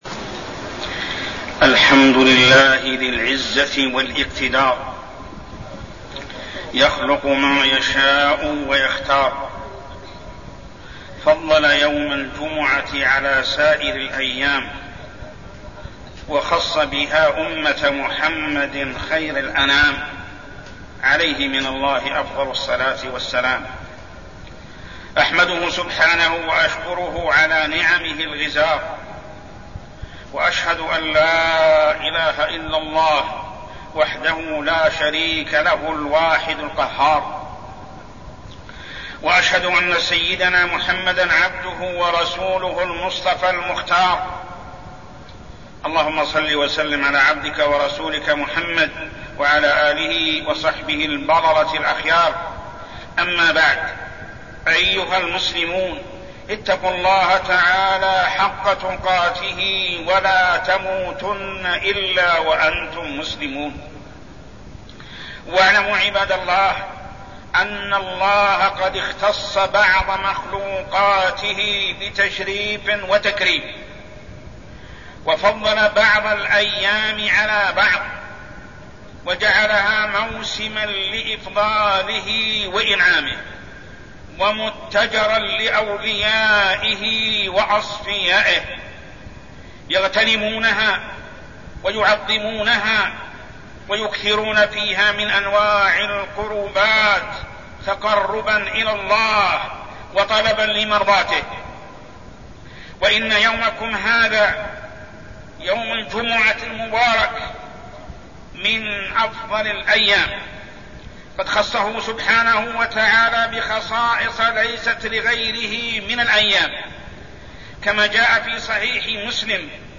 تاريخ النشر ٢٠ شوال ١٤١٤ هـ المكان: المسجد الحرام الشيخ: محمد بن عبد الله السبيل محمد بن عبد الله السبيل خصائص يوم الجمعة